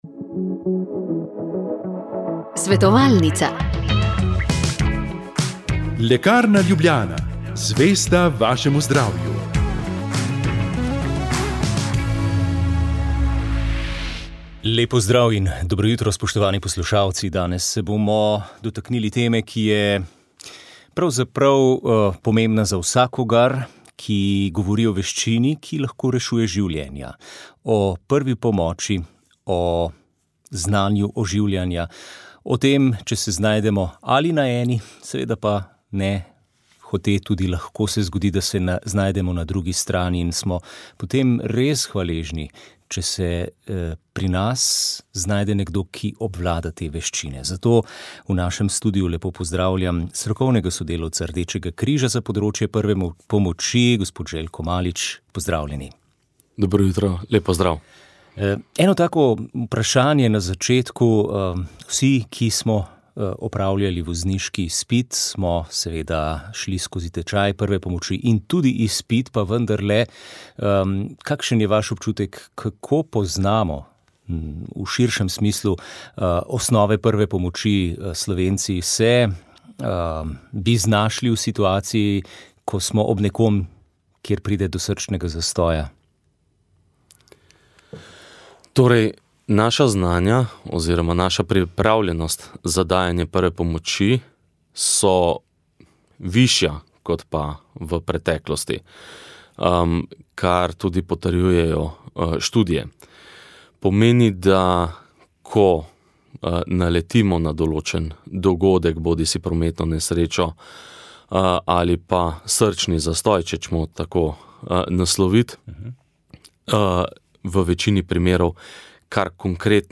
Rožni venec
Molile so redovnice - Hčere Marije Pomočnice.